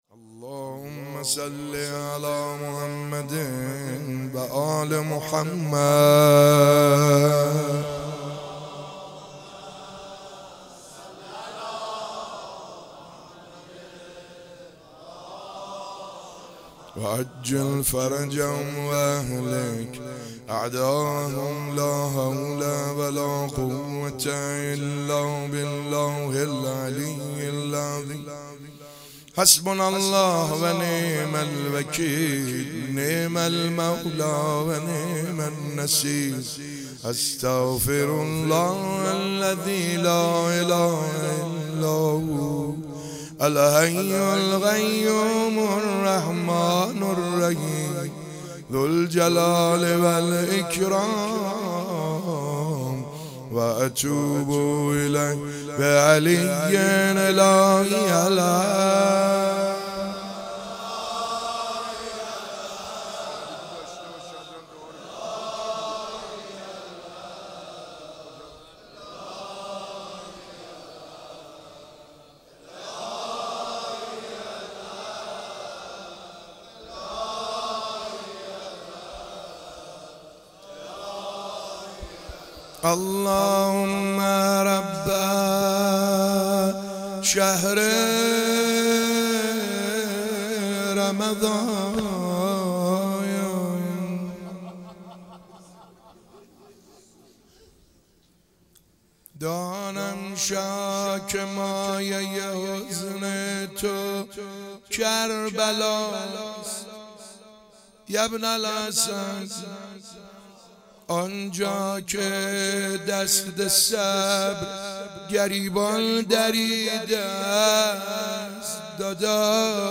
ماه رمضان 96
روضه مداحی